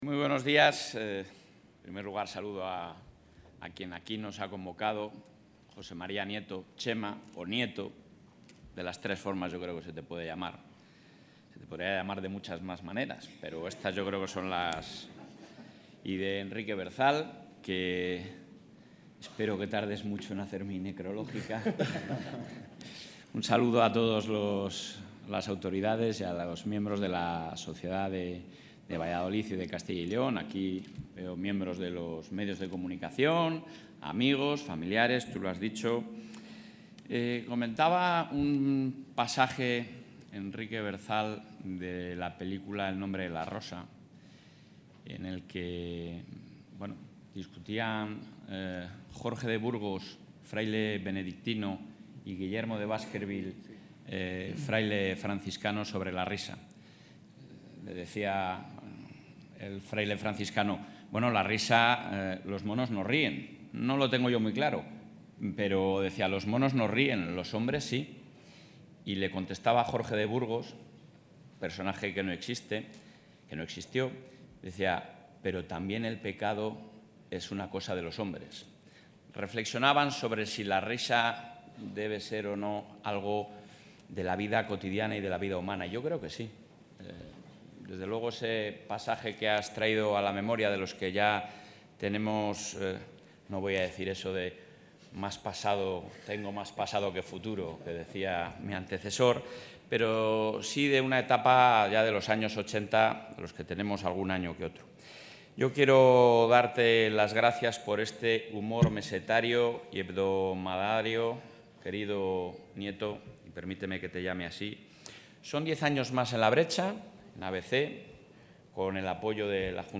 El presidente de la Junta de Castilla y León, Alfonso Fernández Mañueco, ha asistido a la presentación del nuevo libro...
Intervención del presidente.